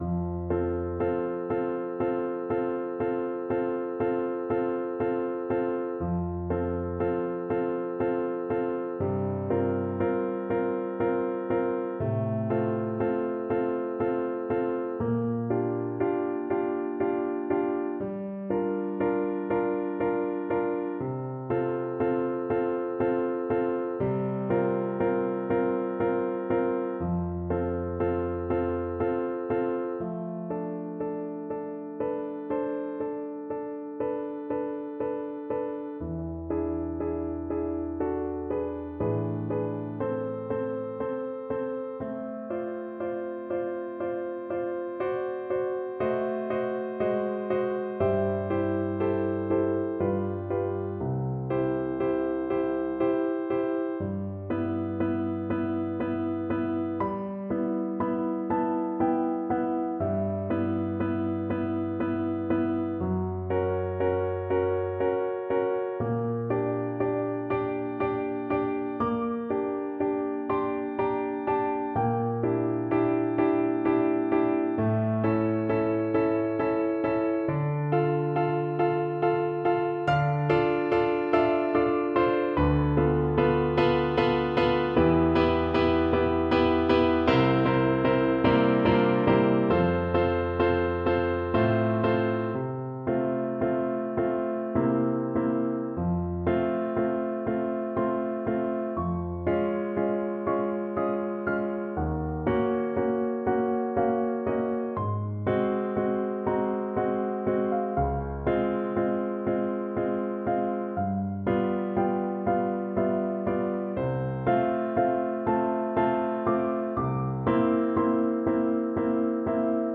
3/4 (View more 3/4 Music)
Andante (=c.60)
Classical (View more Classical French Horn Music)